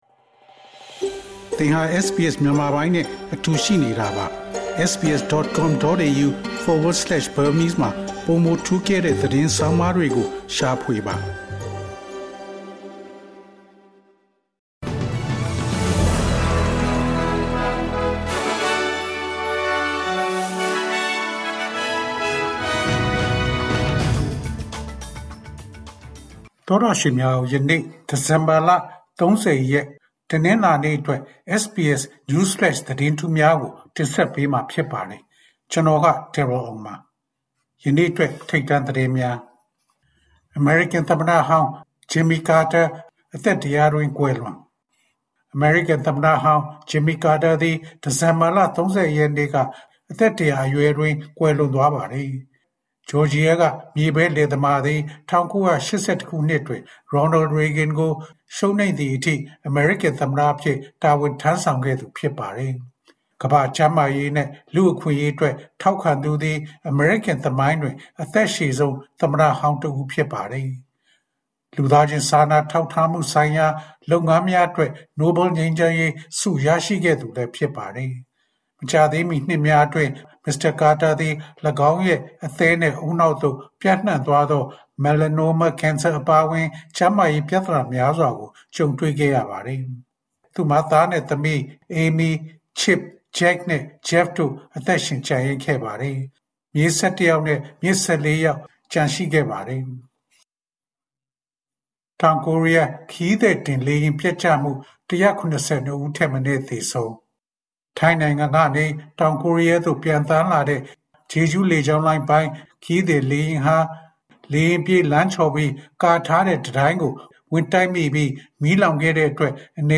SBS မြန်မာ Newsflash Source: SBS